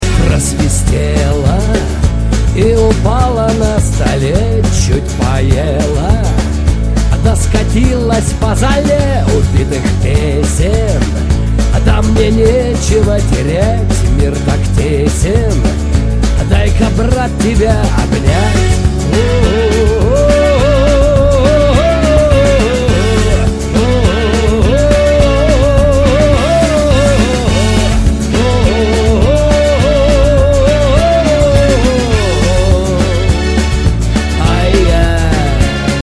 Категория: Rock